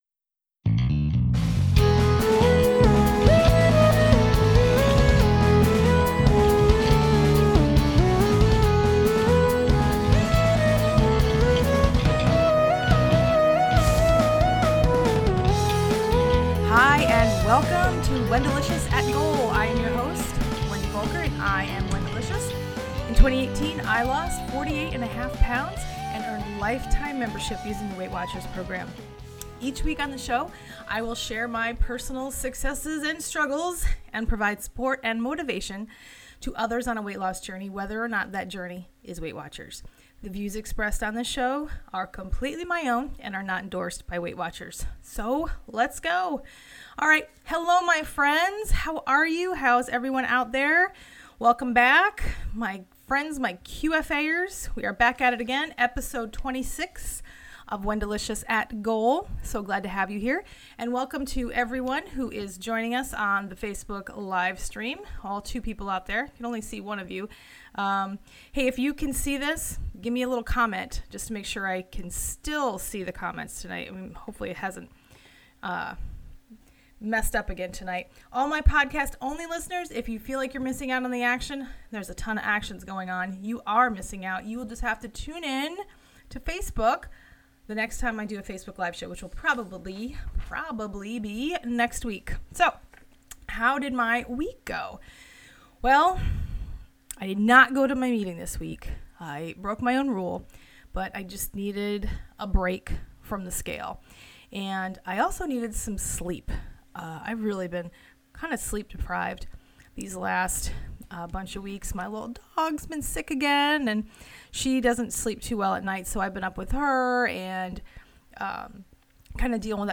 This episode was recorded as a Facebook LIVE video! In it, I share a few little tips and tricks that could help your weight loss efforts.